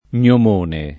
gnomone [ + n’om 1 ne ] s. m.